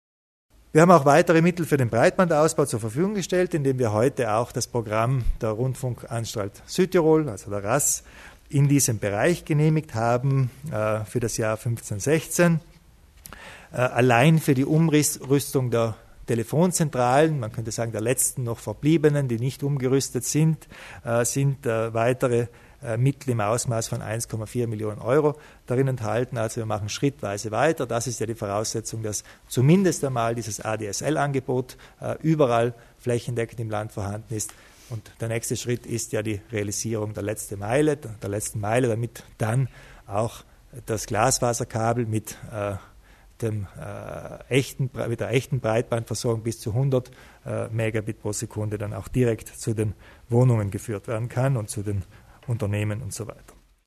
Landeshauptmann Kompatscher zum Ausbau des Breitbandnetzes